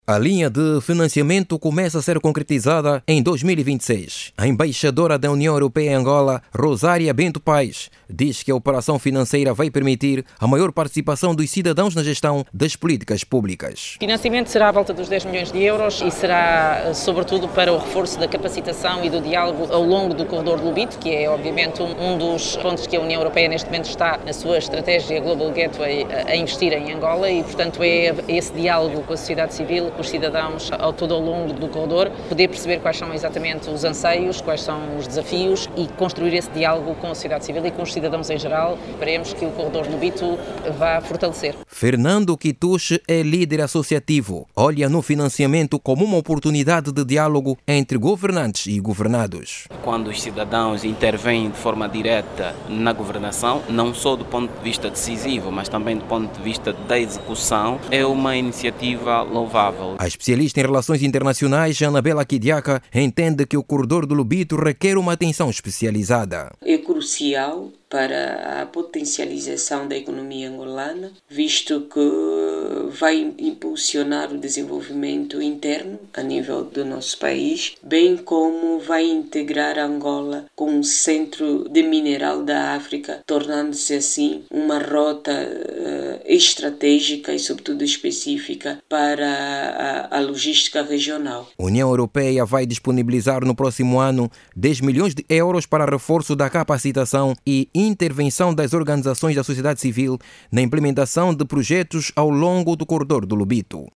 O financiamento pretende ainda envolver a sociedade civil em diferentes fases do processo, reforçando ações voltadas para a promoção do desenvolvimento regional. Jornalista